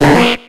Cri de Ramoloss dans Pokémon X et Y.